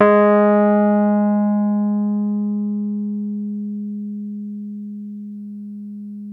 RHODES CL09L.wav